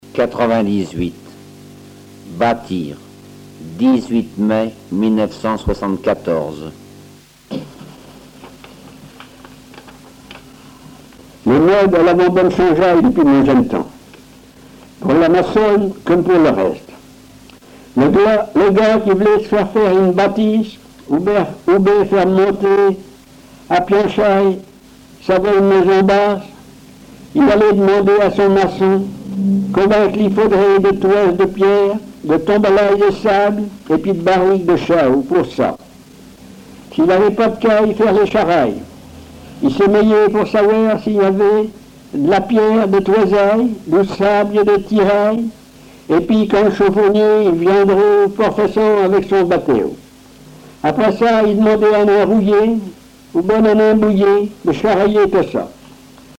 Genre récit
Récits en patois